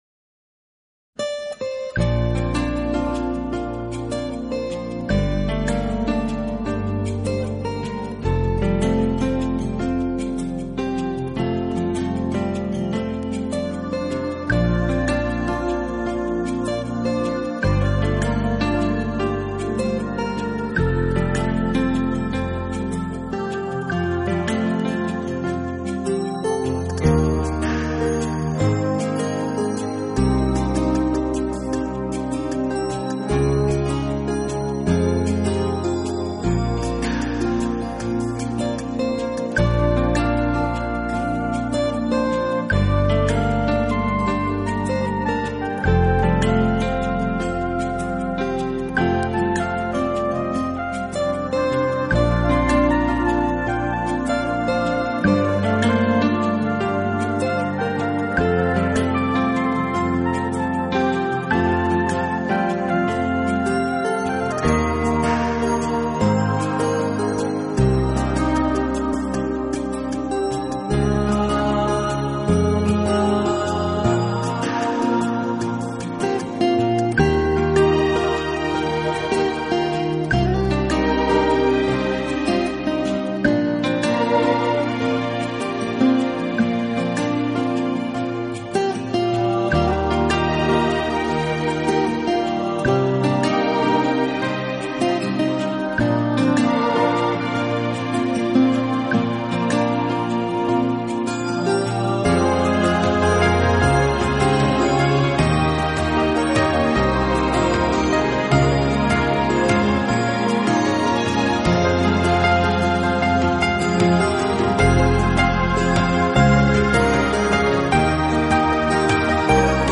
和精选不同，这张并不是纯钢琴，而是真正意义上的轻音乐。